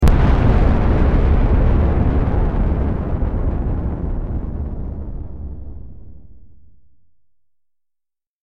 دانلود آهنگ نبرد 14 از افکت صوتی انسان و موجودات زنده
جلوه های صوتی